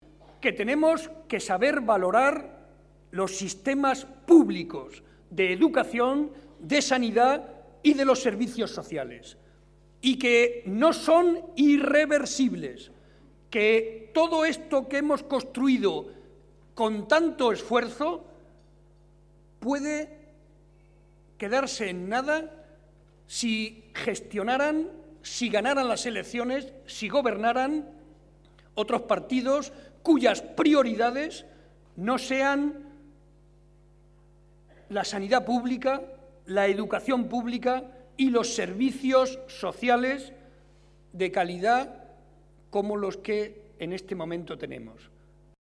Estas son algunas de las manifestaciones que ha hecho esta noche el secretario regional del PSOE y presidente de Castilla-La Mancha, José María Barreda, durante el transcurso de la tradicional cena de Navidad del PSOE de Guadalajara y que ha congregado a más de 400 afiliados y simpatizantes.